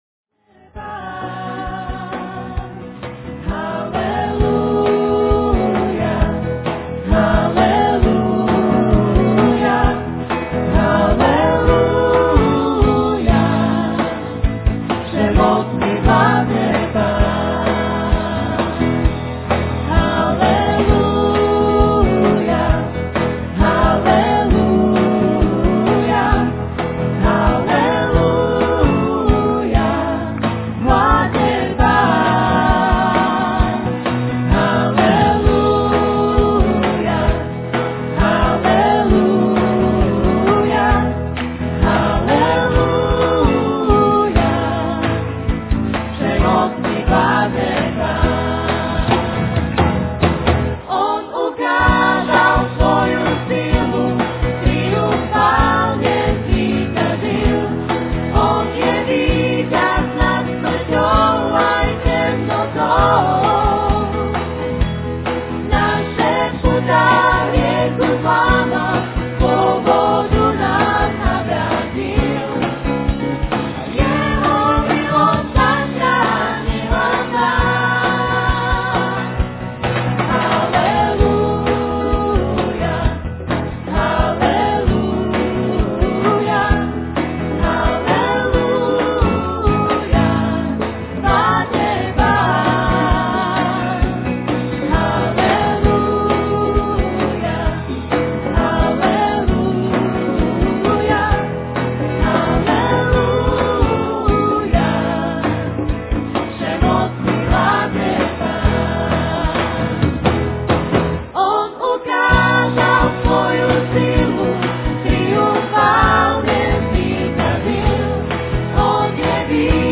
The Touch of God - Slovakia Conference 2003 (Slovakian/english) by David Wilkerson | SermonIndex
In this sermon, the preacher emphasizes the importance of being a man of prayer and brokenness.